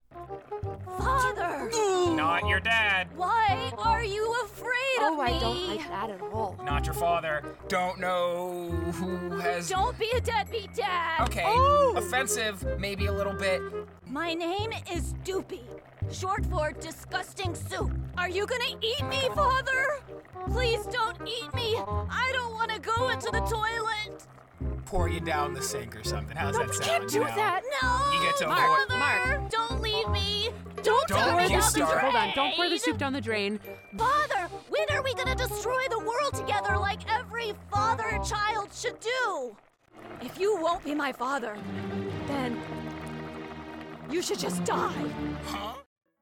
Kids, Animals, & Creatures